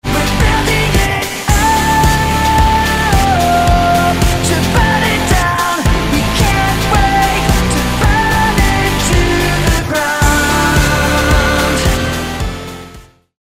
GenreRock
Mischung aus hartem Rock und emotionalem Gesang